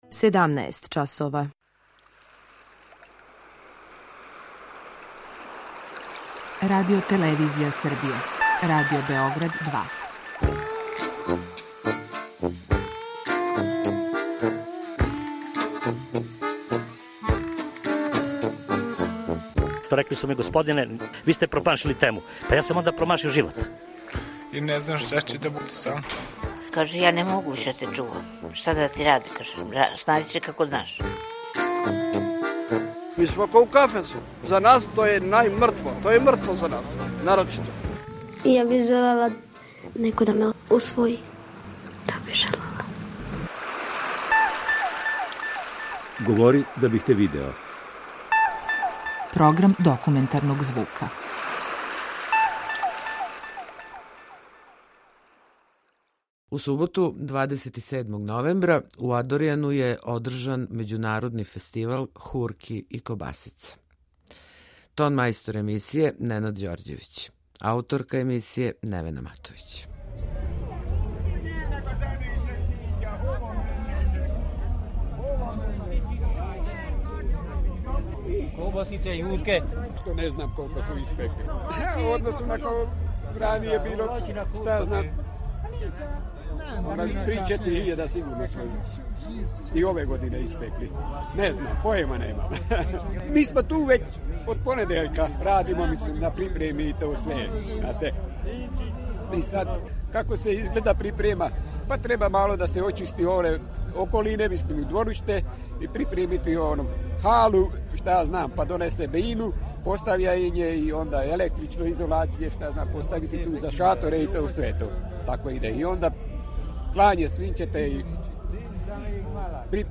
Документарни програм
У суботу, 27. новембра у Адорјану је одржан 10. Међународни фестивал хурке и кобасице.